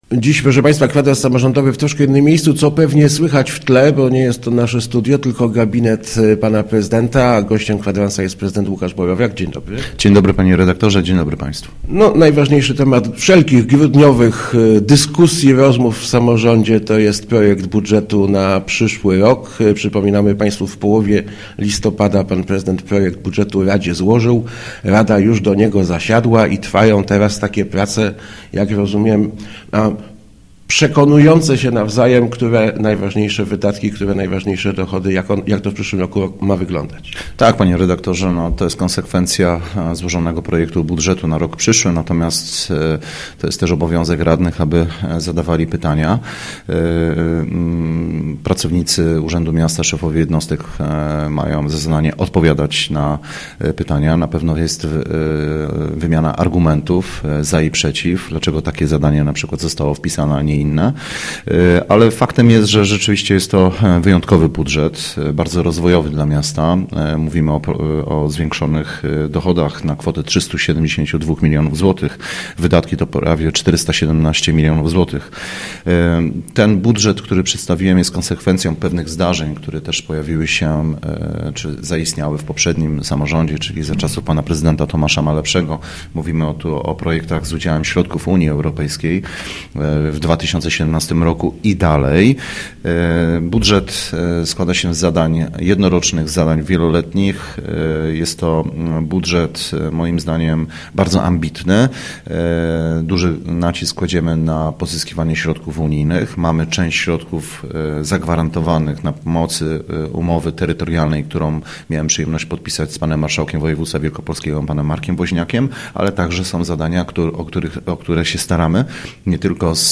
Inwestycje w infrastruktur� b�d� skutkowa� zwi�kszonymi dochodami miasta – mówi� w Kwadransie Samorz�dowym prezydent �ukasz Borowiak.